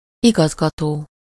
Ääntäminen
IPA: /di.ʁɛk.tœʁ/